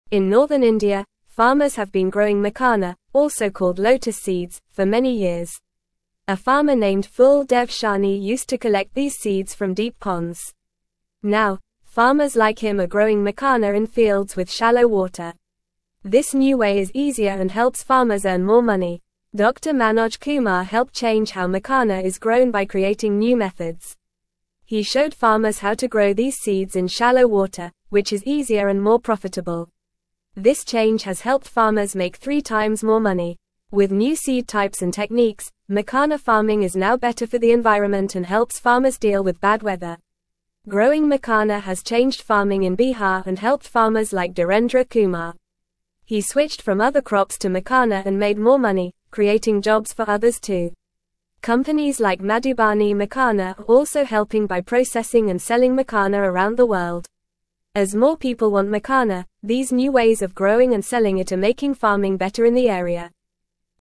Fast
English-Newsroom-Lower-Intermediate-FAST-Reading-Growing-Makhana-Seeds-Helps-Farmers-Earn-More-Money.mp3